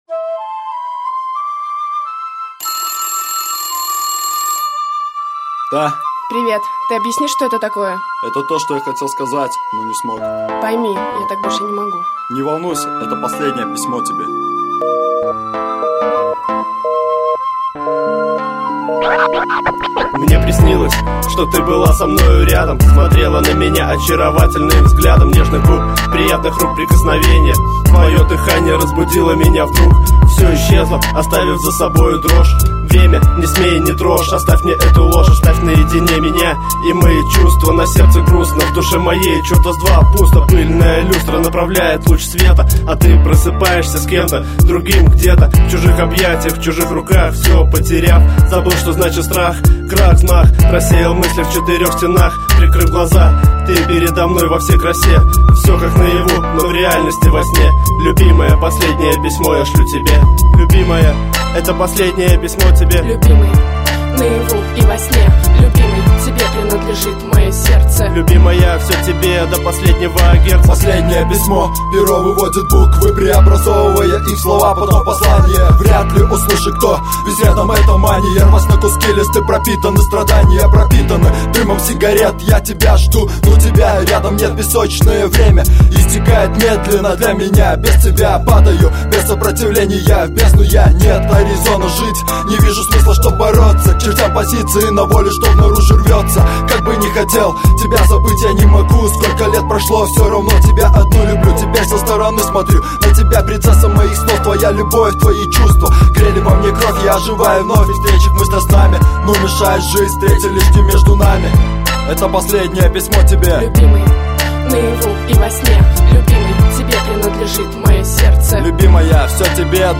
2006 Рэп Комментарии